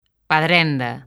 Transcripción fonética
paˈðɾɛnda̝